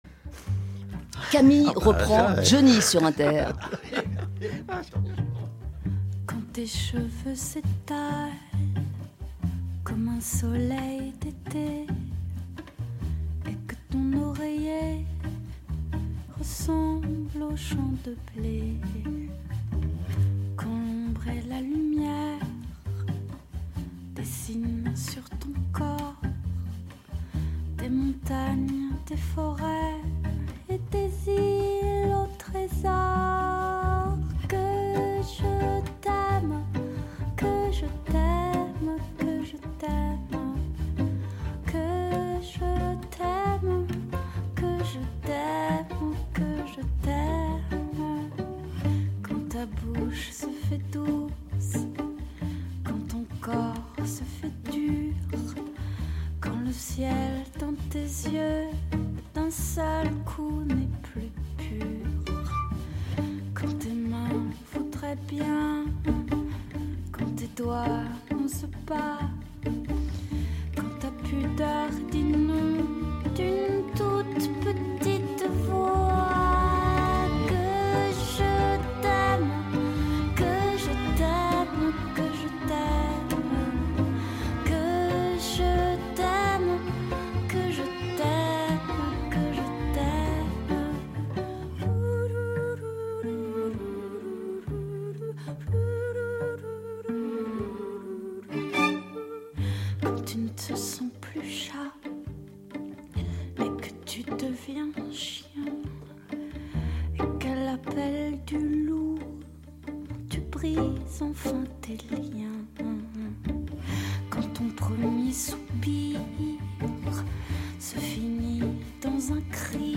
Chansons